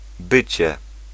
Ääntäminen
UK : IPA : /ˈbiː.ɪŋ/
US : IPA : /ˈbiɪŋ/